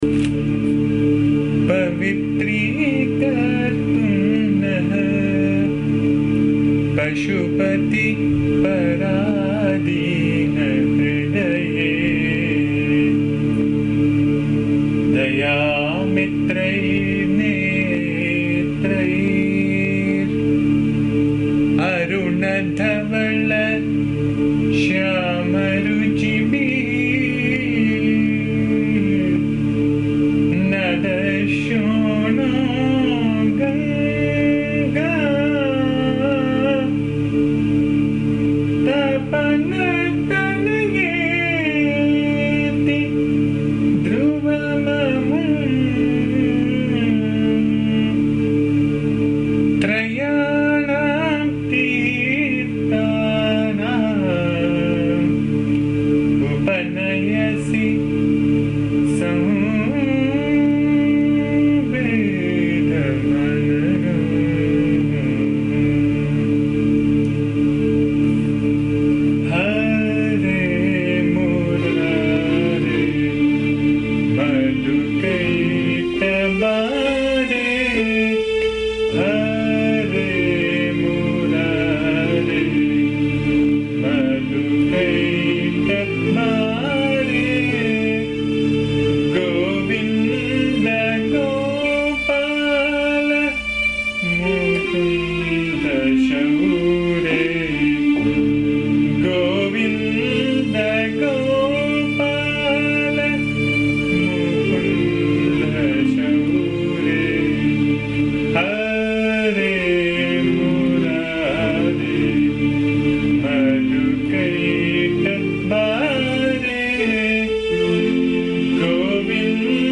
This song is a beautiful bhajan with the names of the Lord and is set in Bhimpalasi or Abheri Raga.
Hence have recorded the song in my voice which can be found here. Please bear the noise, disturbance and awful singing as am not a singer.